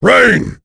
Kaulah-Vox_Skill2-1_b.wav